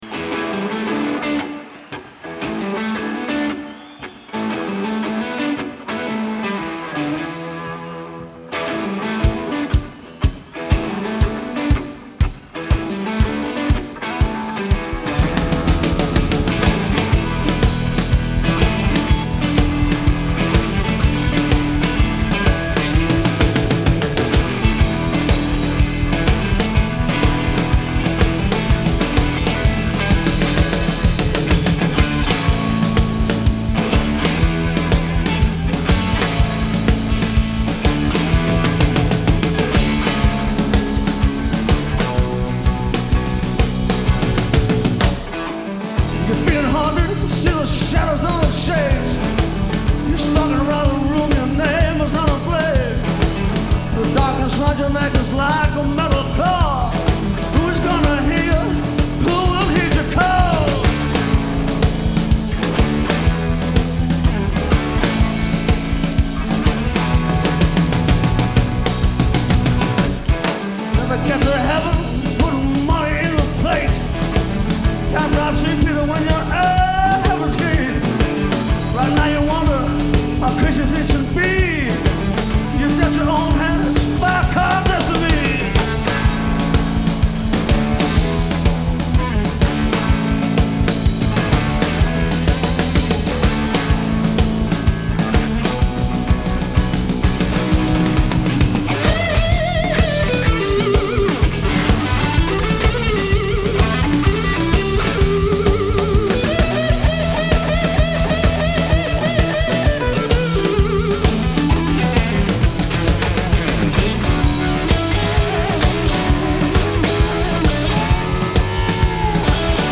Genre : Blues
Live At The Town & Country Club, London, UK